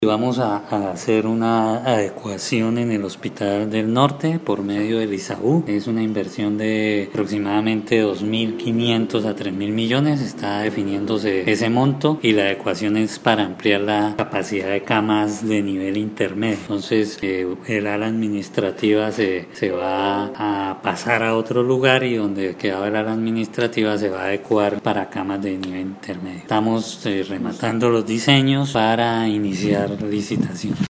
Descargue audio: Iván Vargas, secretario de Infraestructura
Ivan-Vargas-secretario-de-Infraestructura-HLN.mp3